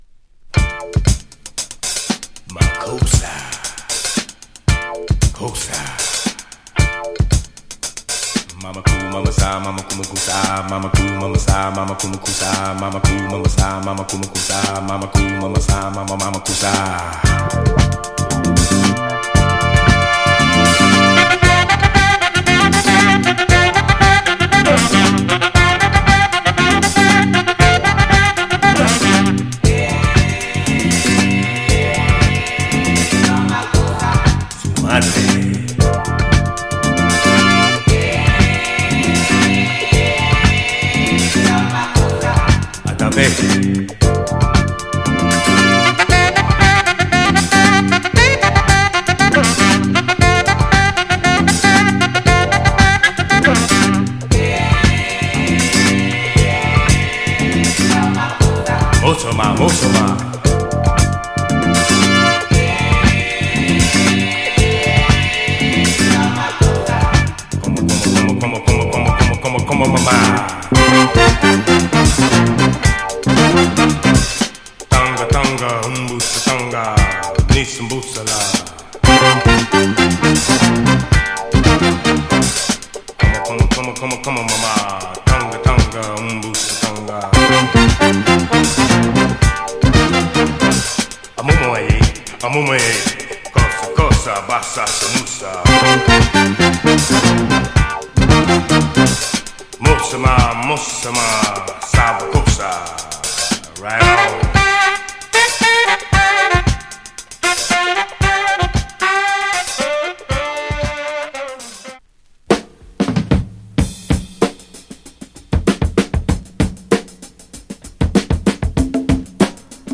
JAZZ
RARE GROOVE CLASSIC !!